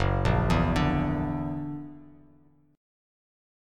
Gb+7 chord